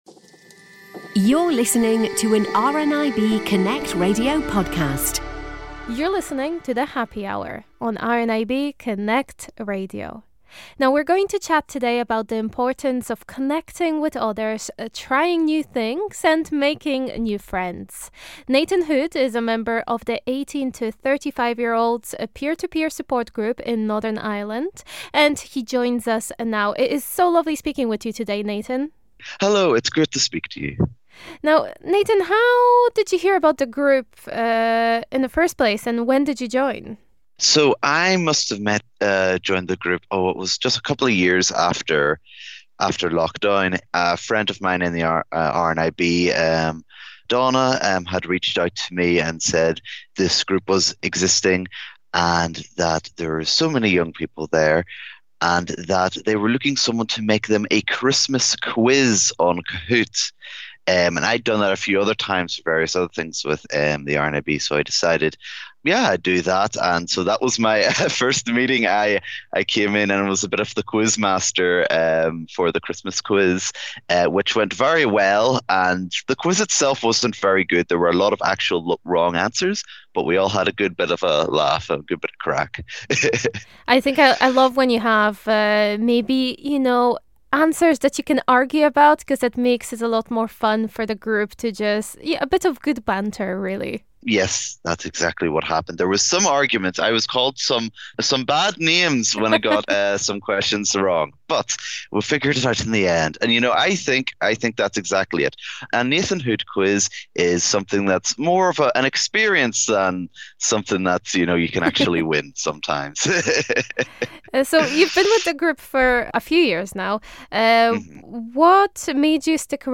We’re going to chat today about the importance of connecting with others in a similar situation as yourself.